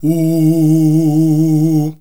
UUUUH   A#.wav